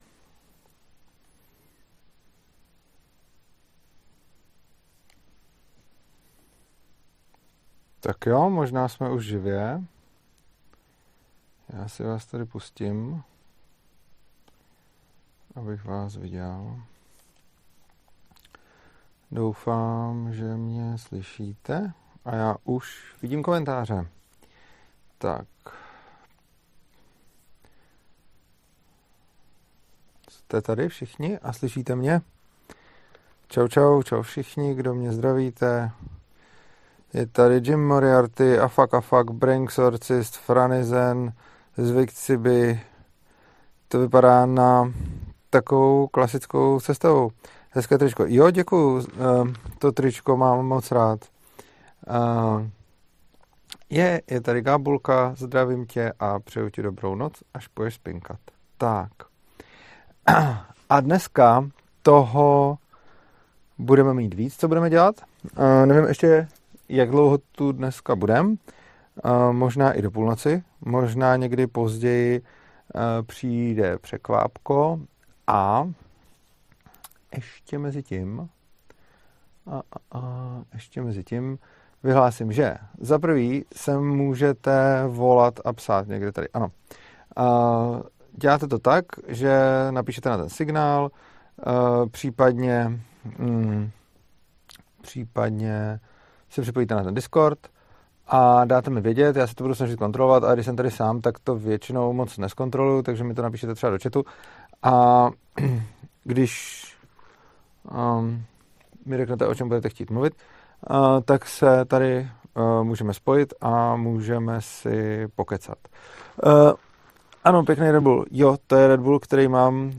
Není anarchista jako anarchista v čajovně Cherubín - Pokud se vám přednáška líbila a shledáváte ji hodnotnou, prosím, pošlete dobrovolný příspěvek v krypto či korunách!